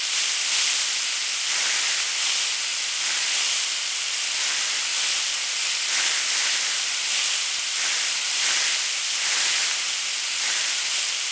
acid_rain.ogg